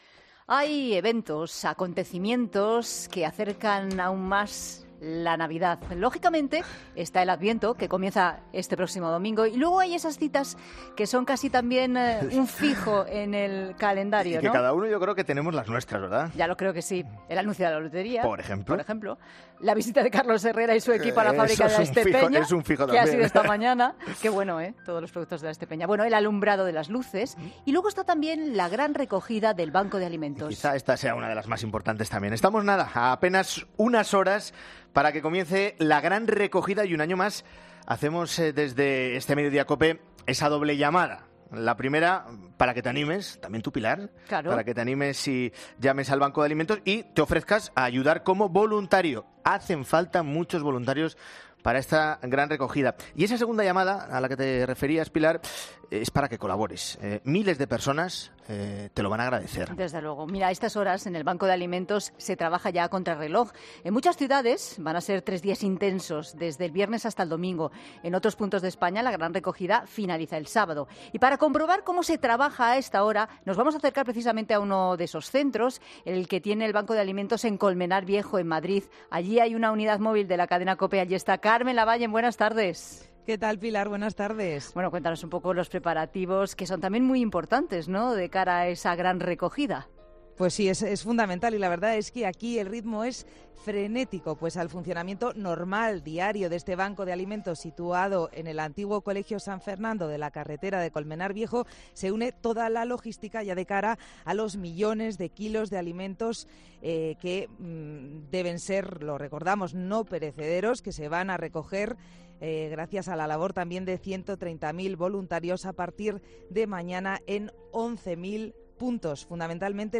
COPE ha podido comprobar el ritmo frenético que allí se vive.